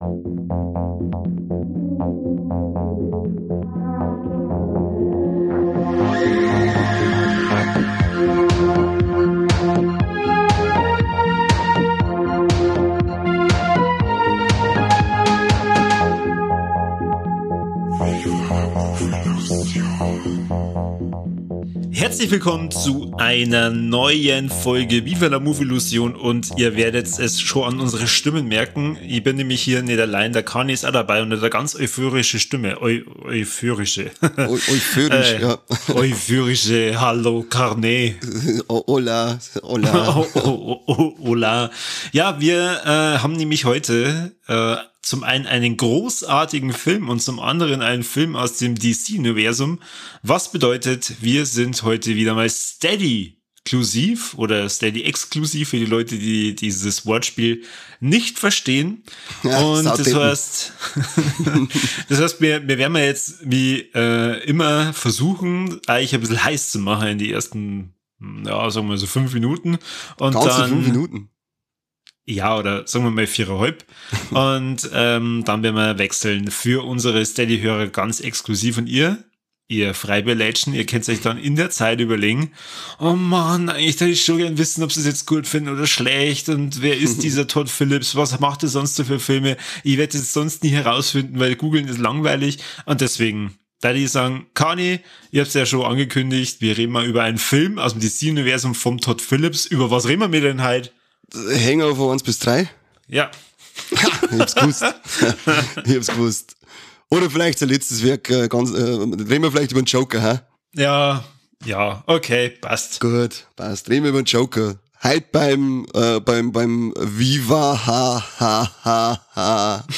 „Viva la Movielución" ist der bayerische Film-Podcast aus Niederbayern – ehrlich, ungefiltert und auf Dialekt.